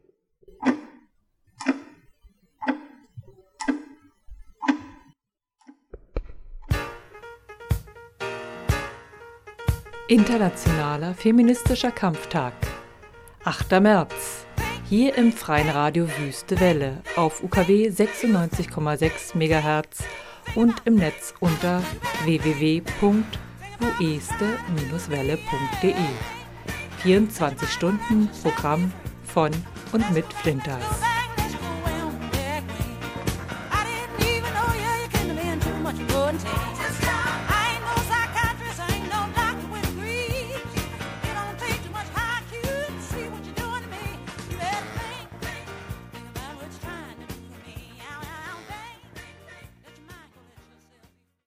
Jingle_8M.mp3